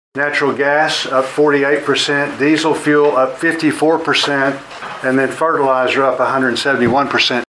Now, Arkansas Senator John Boozman comments on inflation from February last year to February of this year (2022).